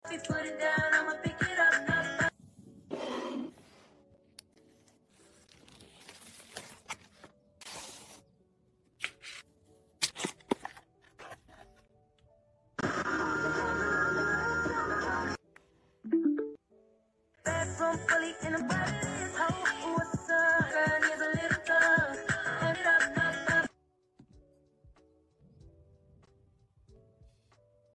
📻✨ Just bought a new radio and it’s giving me all the cozy, old-school vibes. Turning the dial, hearing that soft static before the music comes in — it’s like a little time machine in my hands.